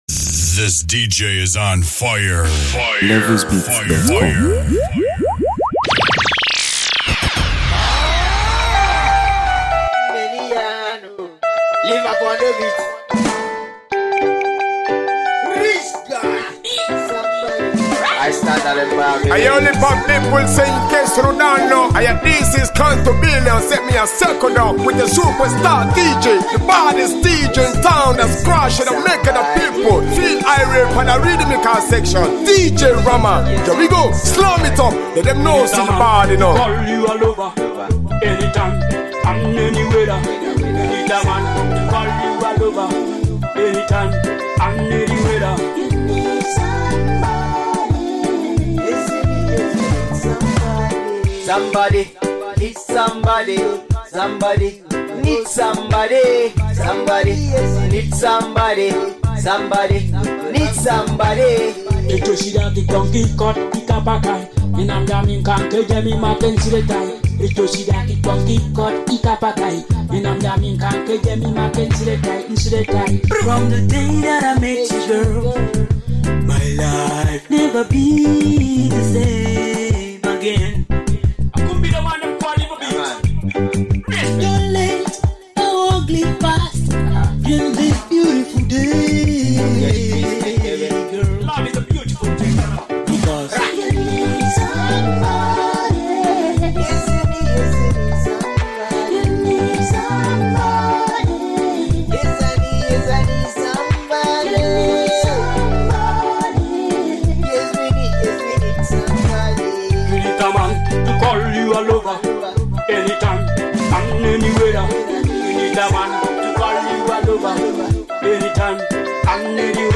” an enthralling reggae mix.